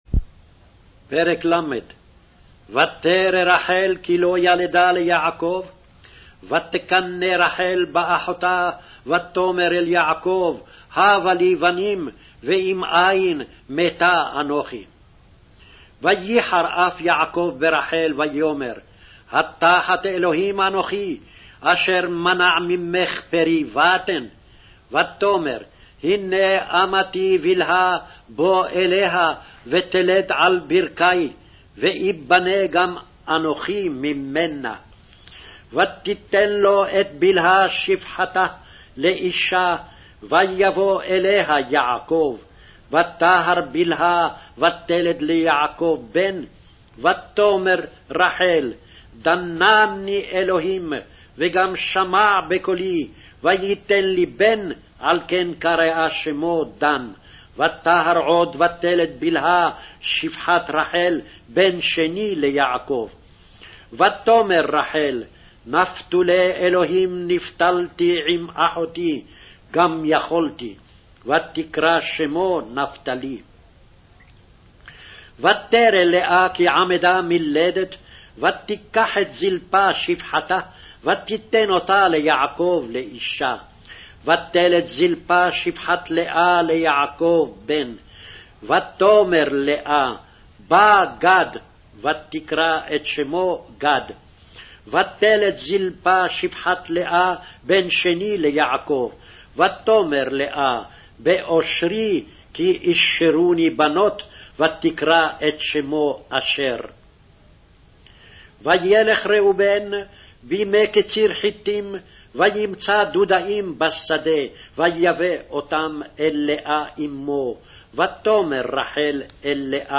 Hebrew Audio Files: